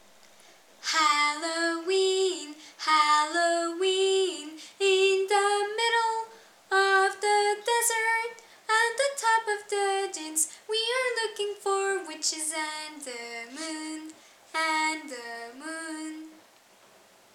and Halloween as a nursery rhyme:
♫ (Tune of Brother John)